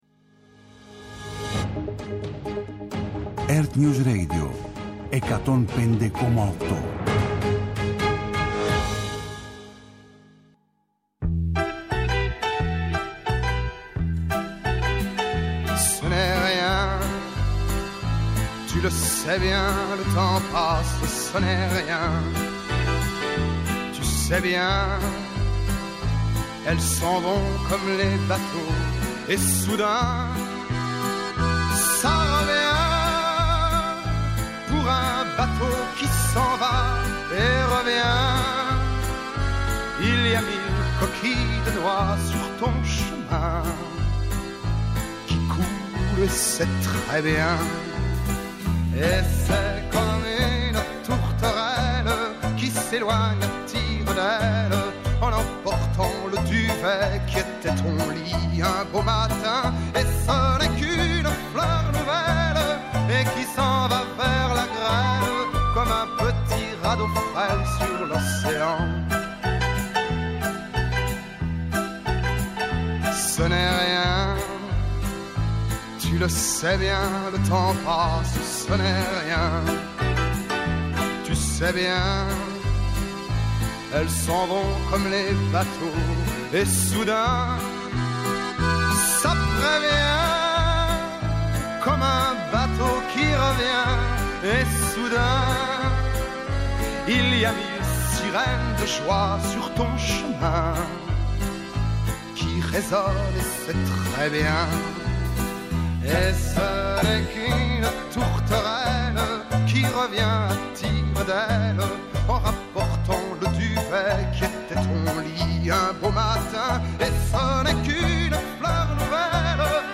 Ενημέρωση με έγκυρες πληροφορίες για όλα τα θέματα που απασχολούν τους πολίτες. Συζήτηση με τους πρωταγωνιστές των γεγονότων.